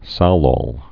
(sălôl, -ōl, -ŏl)